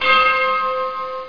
bell.mp3